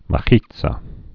(mə-ētsə, -ē-tsä)